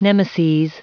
Prononciation du mot nemeses en anglais (fichier audio)
Prononciation du mot : nemeses